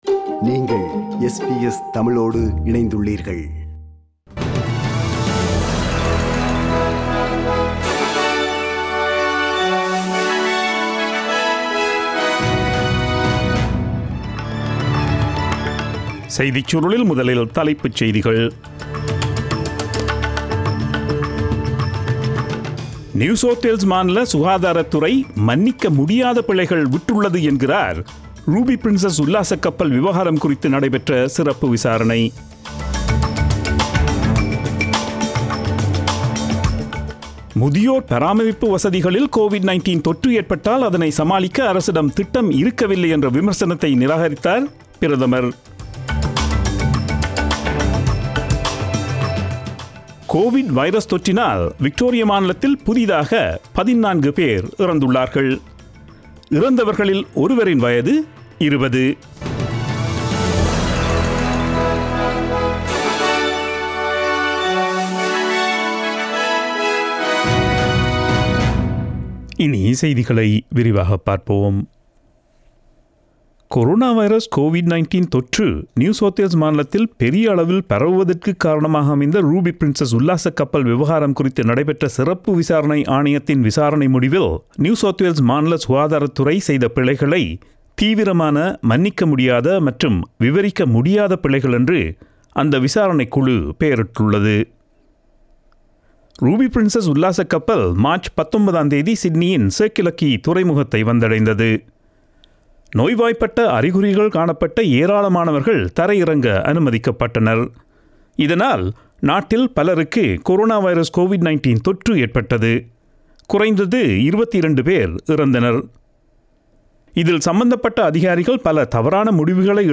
Australian news bulletin aired on Friday 14 August 2020 at 8pm.